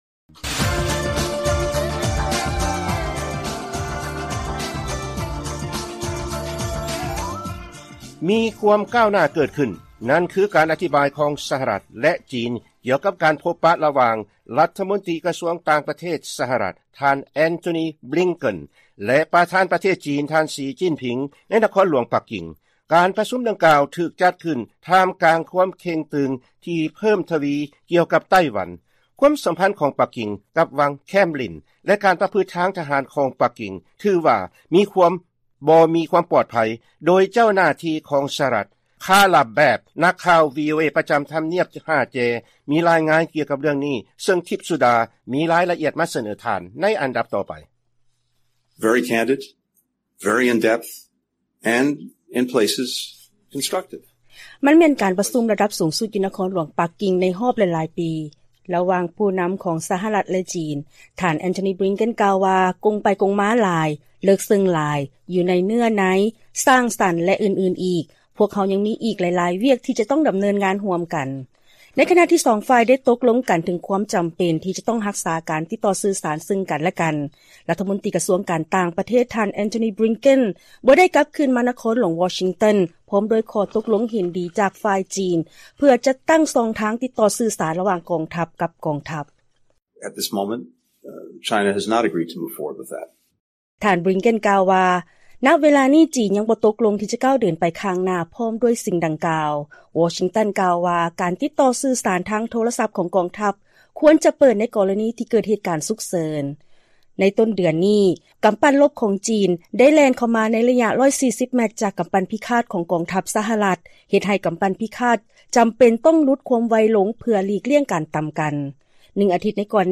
ລາຍງານກ່ຽວກັບການກ່າວປາໄສຂອງທ່ານບລິງເກັນ ກ່ຽວກັບການໄປຢ້ຽມຢາມປັກກິ່ງ